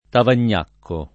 Tavagnacco [ tavan’n’ # kko ]